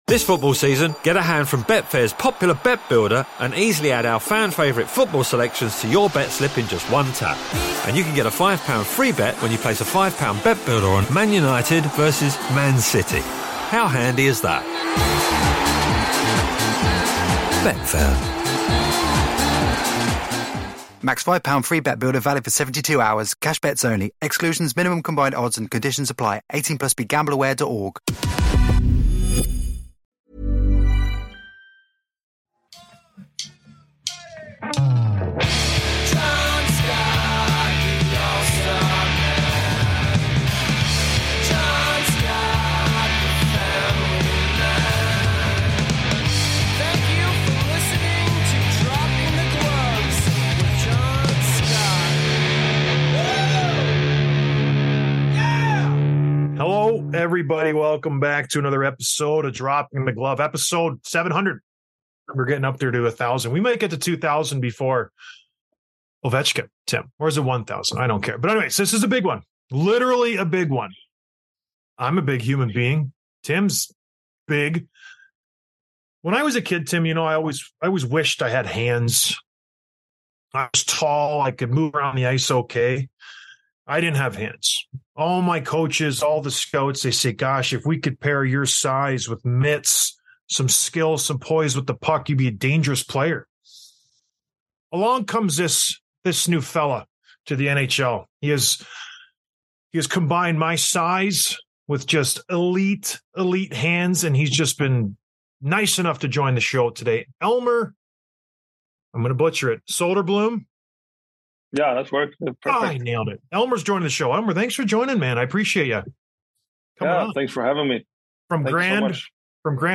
Interview with Elmer Soderblom, Detroit Red Wings